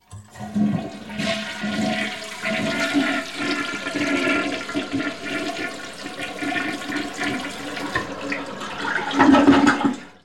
The flushing sound - Eğitim Materyalleri - Slaytyerim Slaytlar